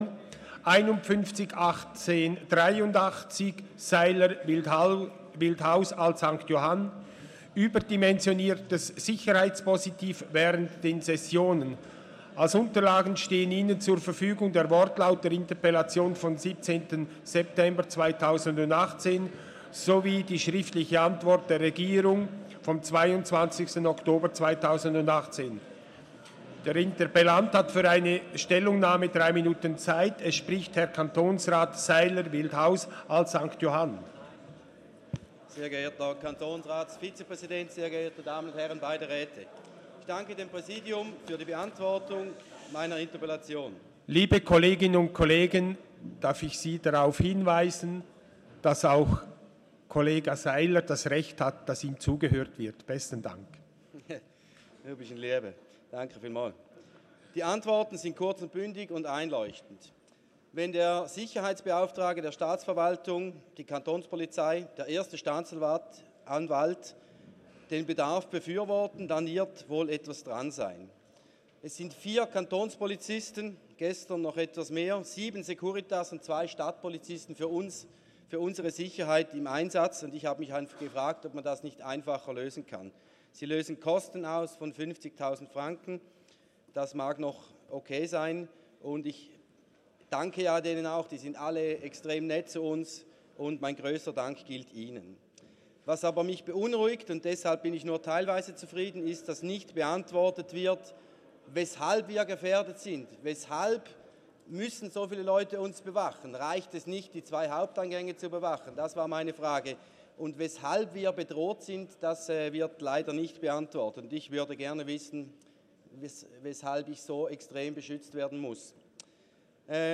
19.2.2019Wortmeldung
Session des Kantonsrates vom 18. und 19. Februar 2019